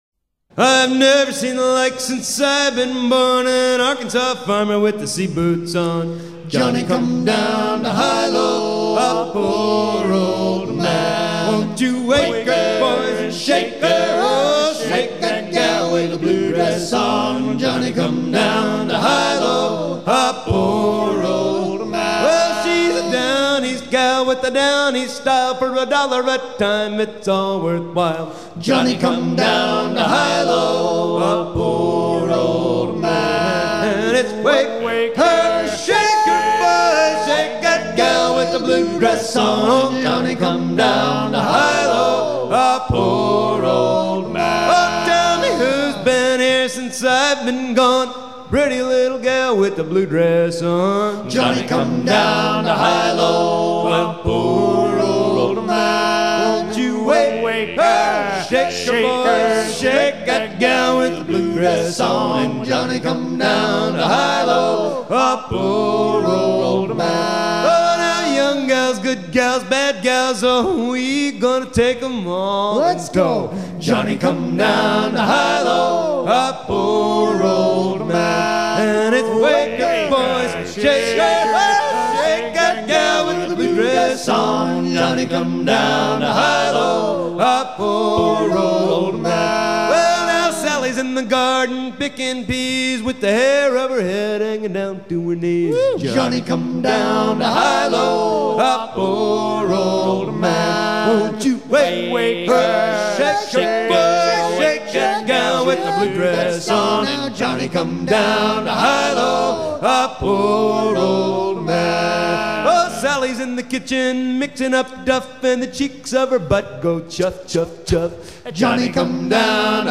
Johnny comes down to hilo Détails de l'archive Titre Johnny comes down to hilo Origine du titre : Editeur Note chant caractéristique dans les ports cotonniers du Sud des Etats-Unis. Mélodie d'origine irlandaise
à virer au cabestan
Chants des marins du monde